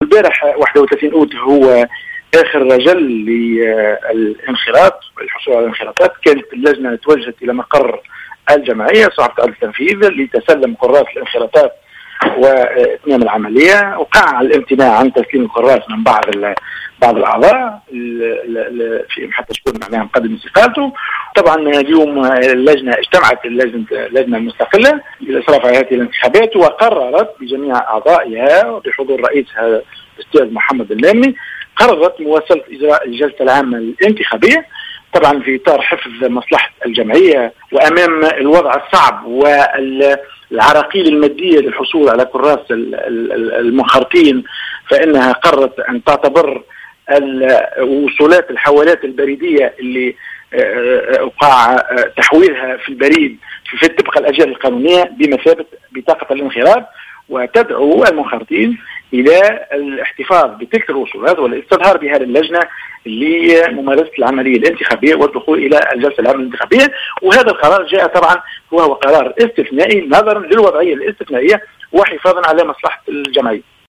في تصريح لجوهرة اف ام